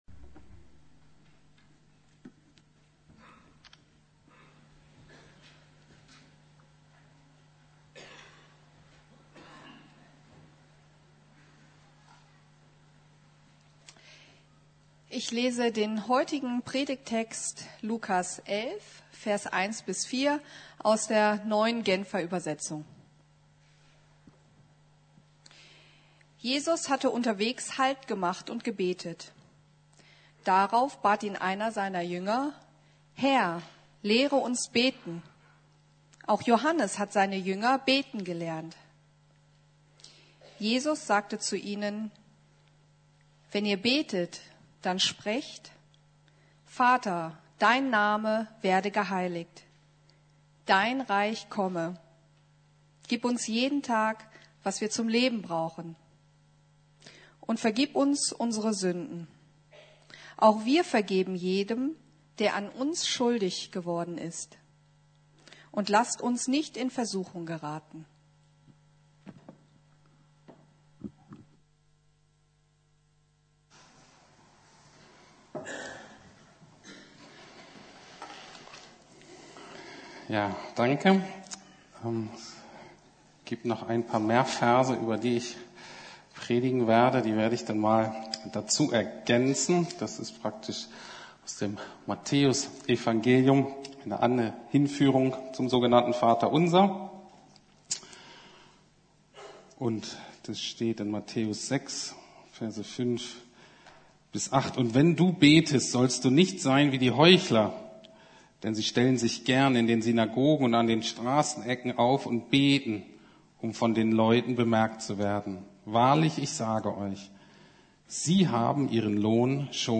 Herr, lehre uns beten! ~ Predigten der LUKAS GEMEINDE Podcast